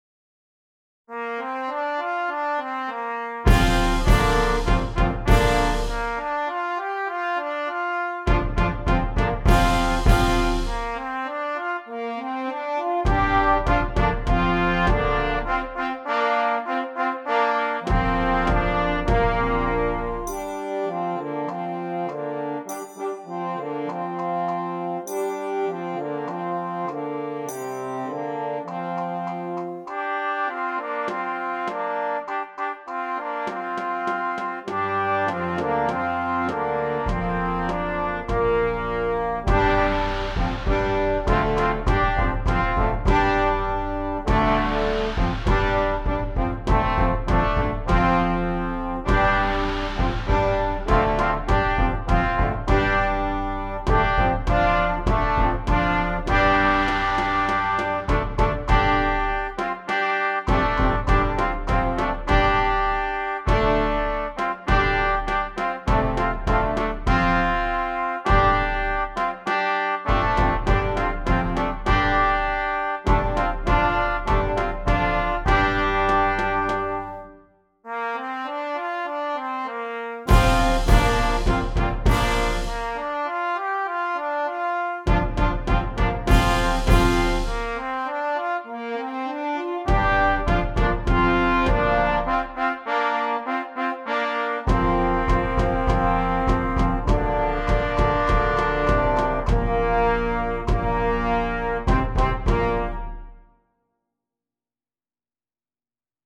Brass Choir
This gives it a true Asian sonority.